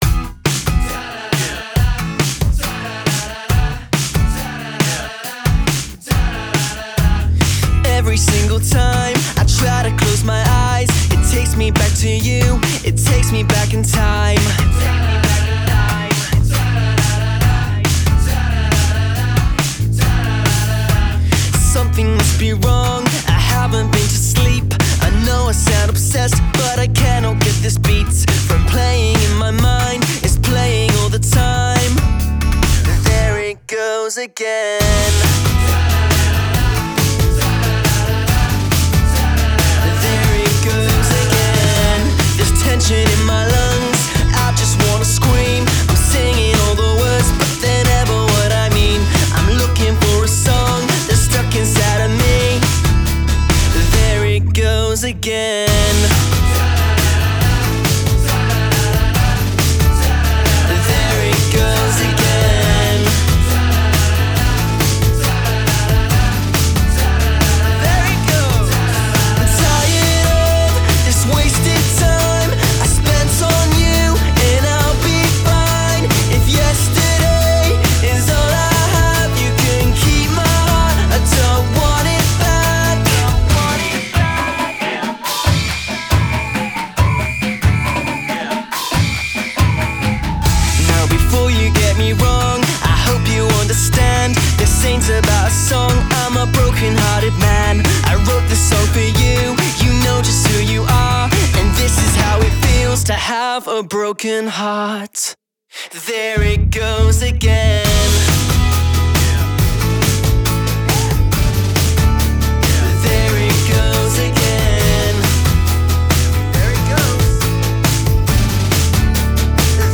distinctly more sweet and hooky.
was bit more indie rock band